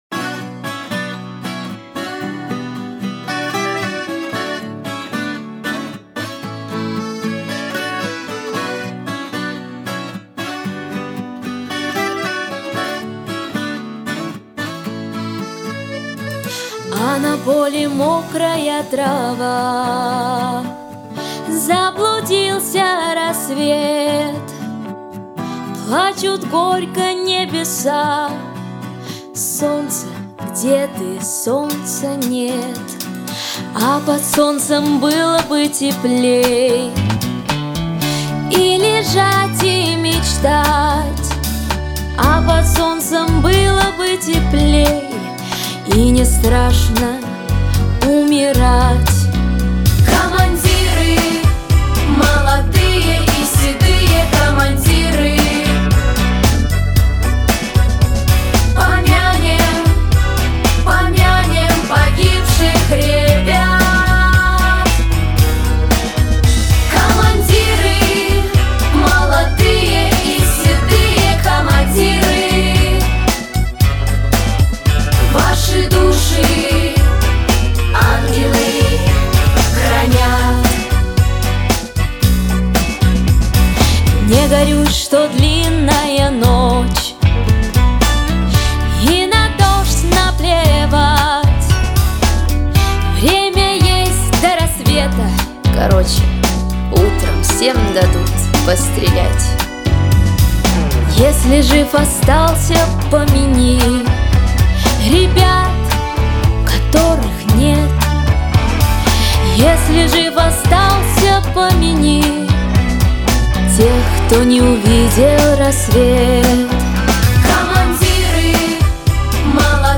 • Качество: Хорошее
• Жанр: Детские песни
военные песни, подростковые песни